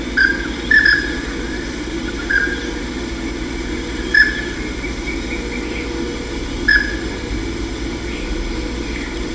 Anas georgica spinicauda - Pato maicero
patomaicero.wav